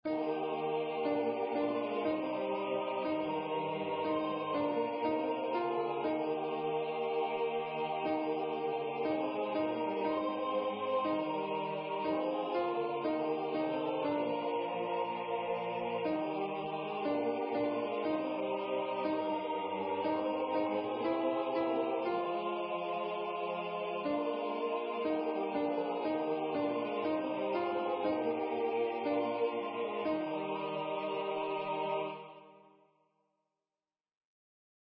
with Accompaniment
(SATB no words)
Instruments: Keyboard
AbideWithMeAltoP.mp3